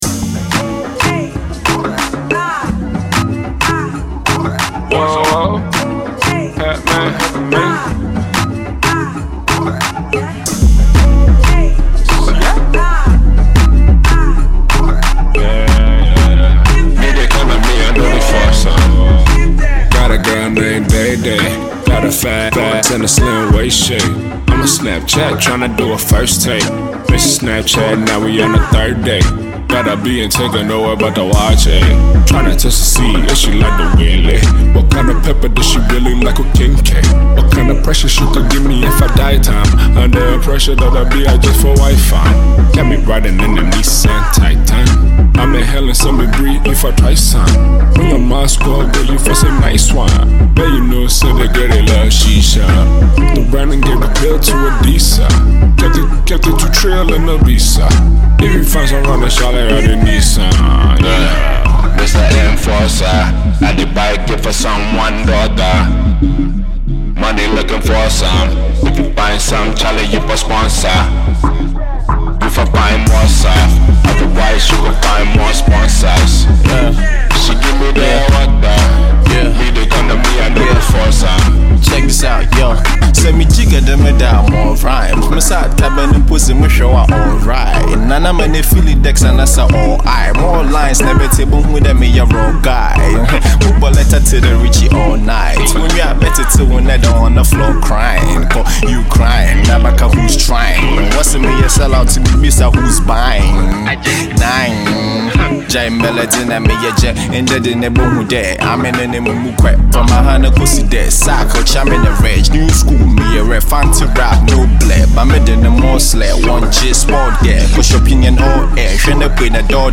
rapper